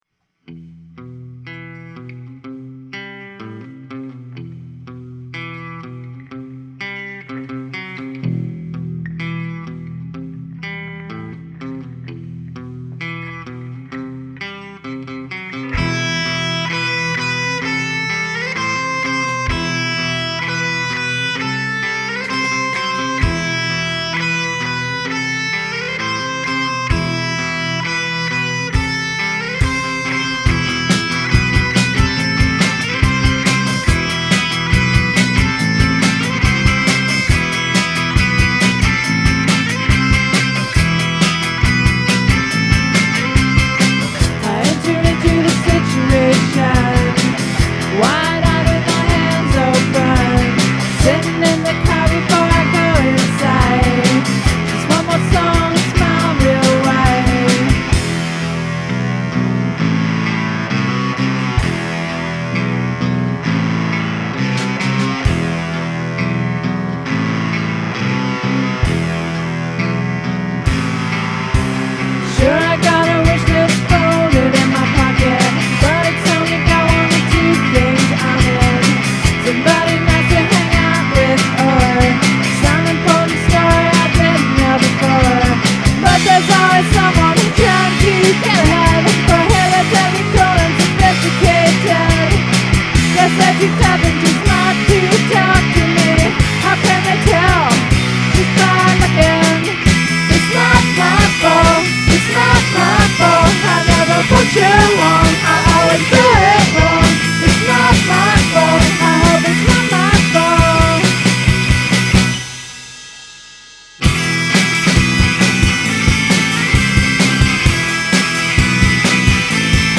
rhythm guitar